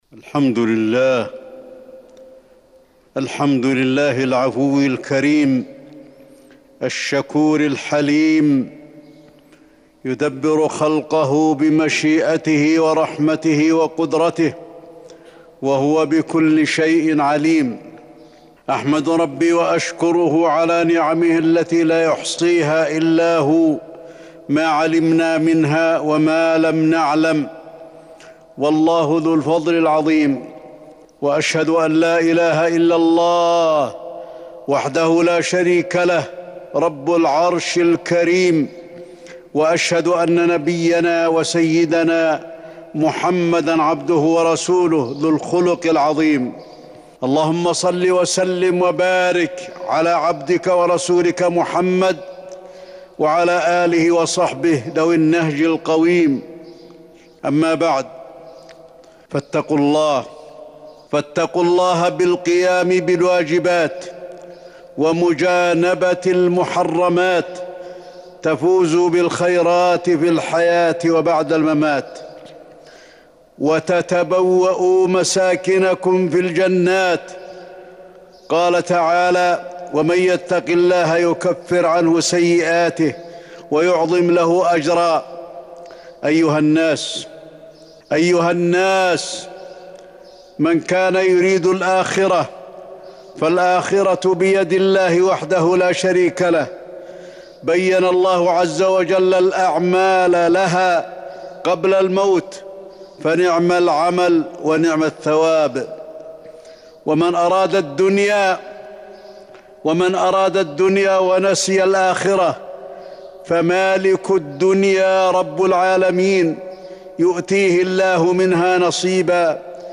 تاريخ النشر ١٧ شعبان ١٤٤١ هـ المكان: المسجد النبوي الشيخ: فضيلة الشيخ د. علي بن عبدالرحمن الحذيفي فضيلة الشيخ د. علي بن عبدالرحمن الحذيفي سؤال الله العافية